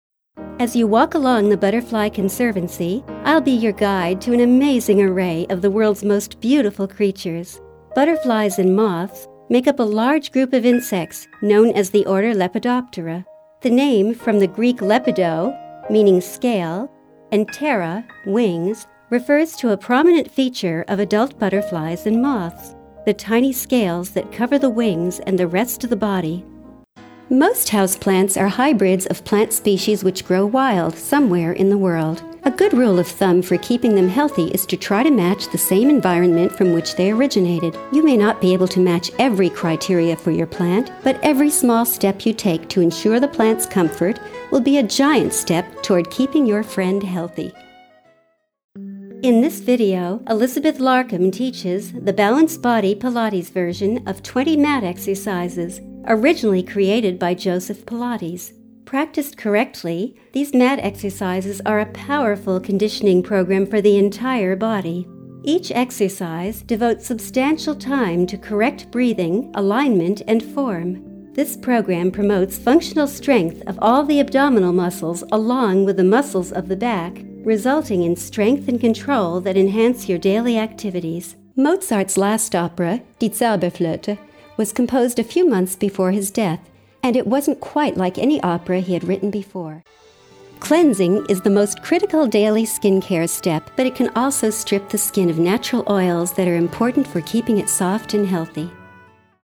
warm, clear diction, professional, expressive, engaging young adult, middle age, senior E-Learningk, Medical, Technical, Corporate training, educational, promotions, business, websites, audio books, children\'s stories, IVR, English language learning, real estate, travel logs narrations
mid-atlantic
Sprechprobe: eLearning (Muttersprache):